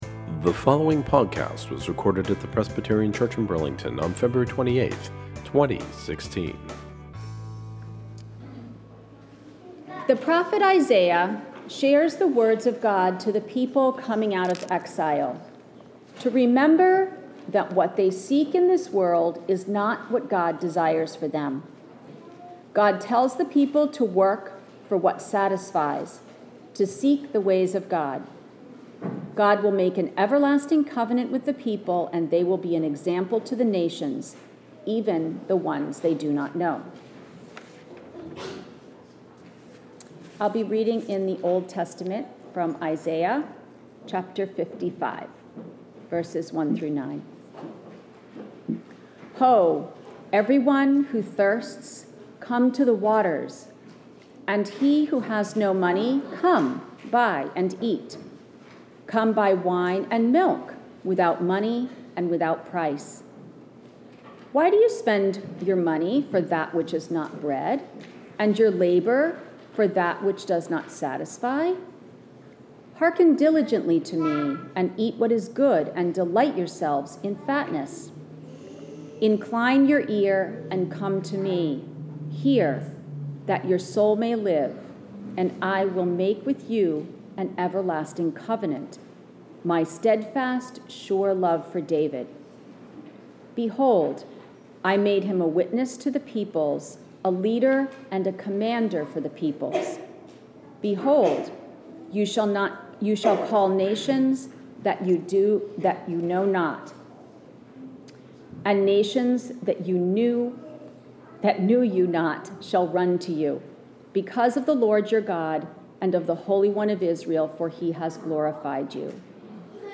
Catching up, this is the sermon from February 28.